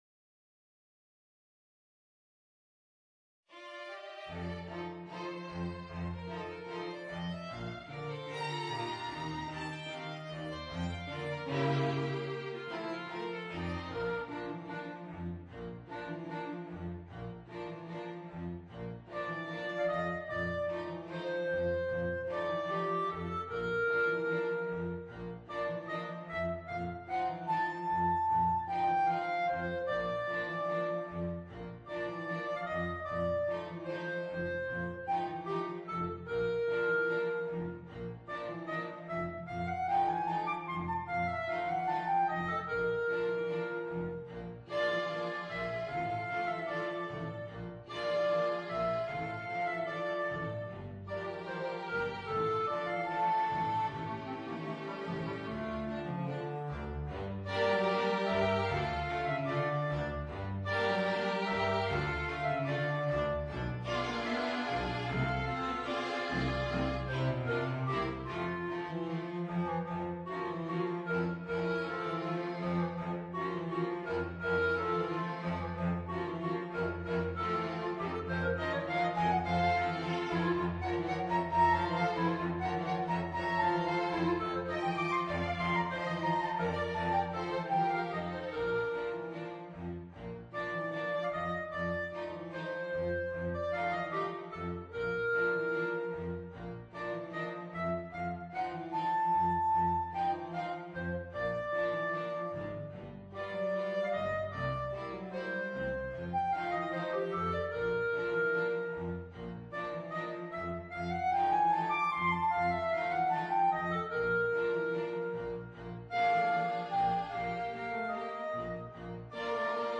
per clarinetto e orchestra d’archi o quartetto d’archi